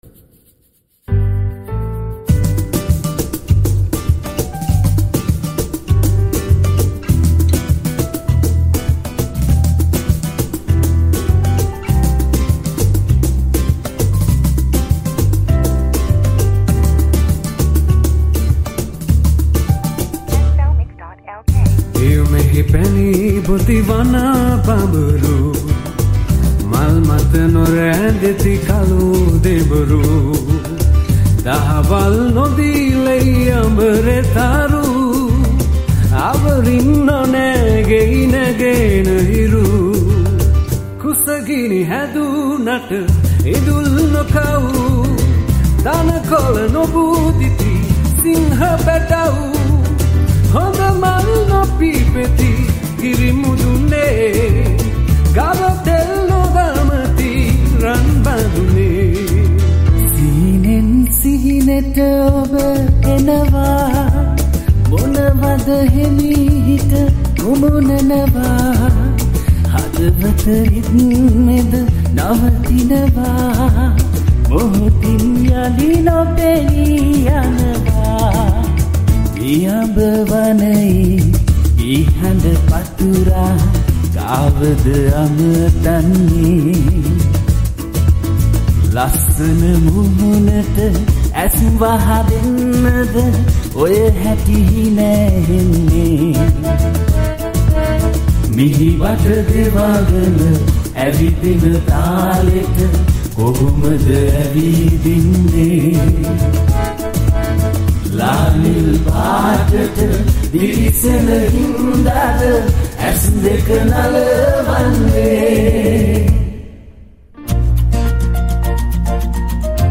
High quality Sri Lankan remix MP3 (4.9).
Covers